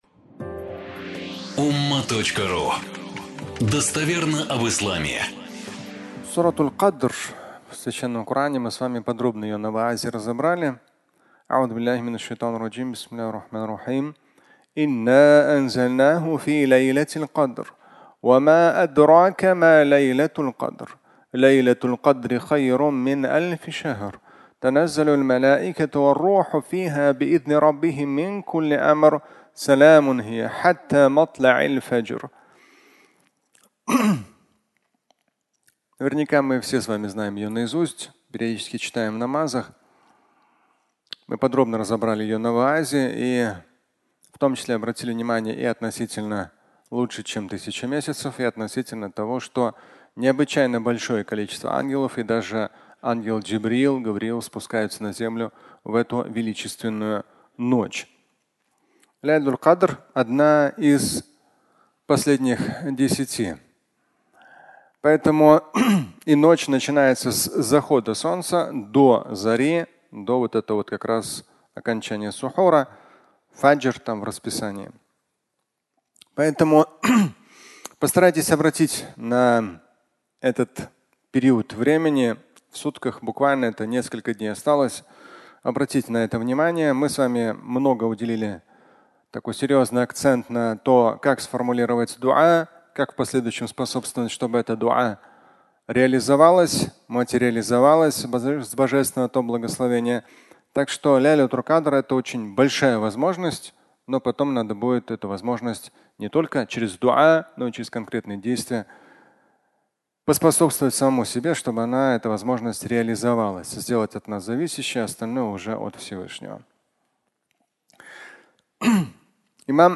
Не жди, но проси (аудиолекция)